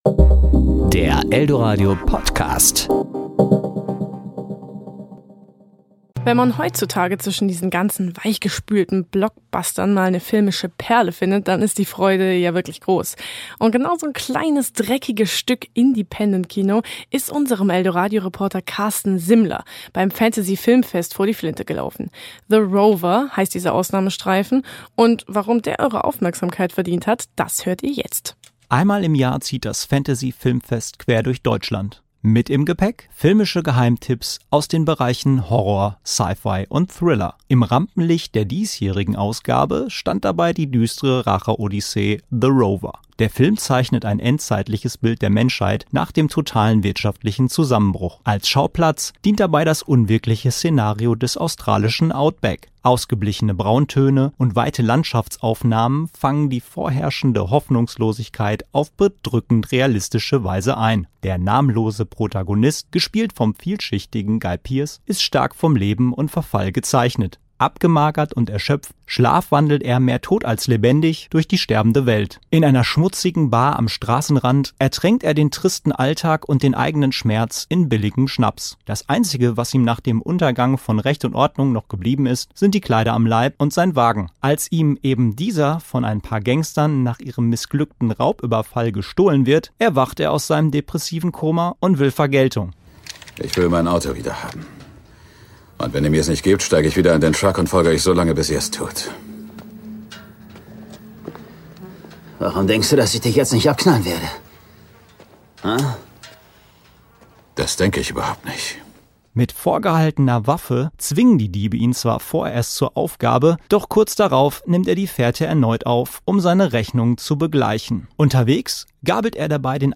Filmrezension - The Rover